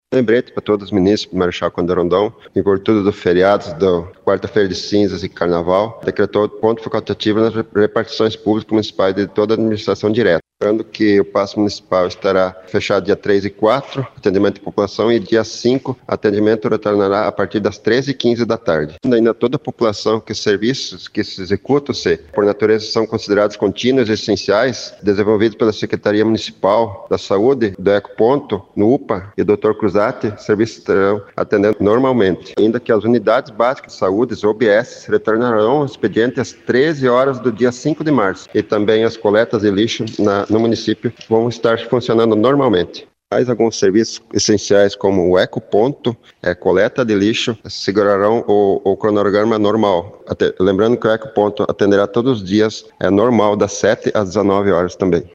O Secretário de Gestão de Governo, Gilmar Dattein, destaca que os serviços essenciais continuam sendo ofertados normalmente………..OUÇA ENTREVISTA